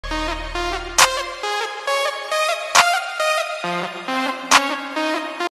Play, download and share uzaylı sesi original sound button!!!!